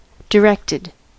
Ääntäminen
Ääntäminen US : IPA : [dɪ.ˈɹɛk.tɪd] Tuntematon aksentti: IPA : /daɪ.ˈɹɛk.tɪd/ Haettu sana löytyi näillä lähdekielillä: englanti Käännös Adjektiivit 1. dirigido Directed on sanan direct partisiipin perfekti.